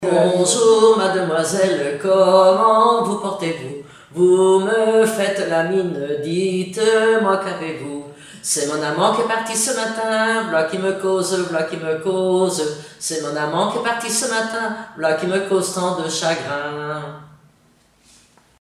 Divertissements d'adultes - Couplets à danser
polka piquée
Pièce musicale inédite